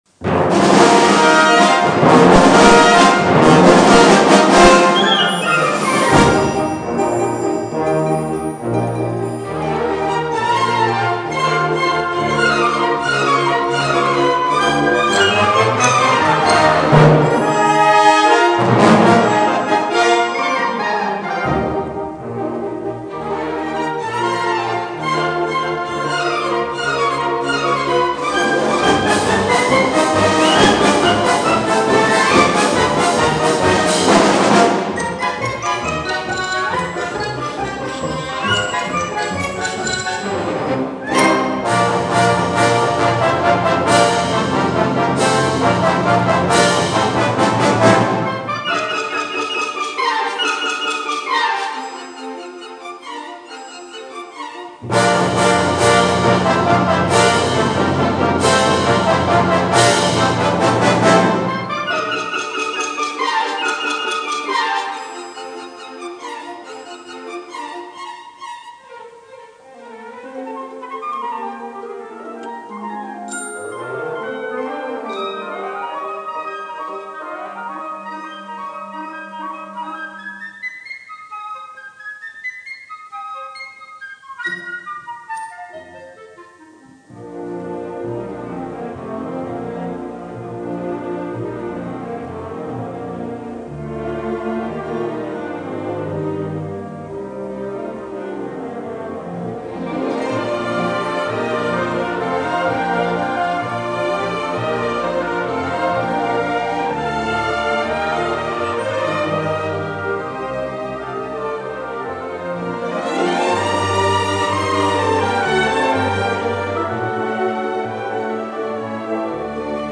violino
GenereMusica Classica / Sinfonica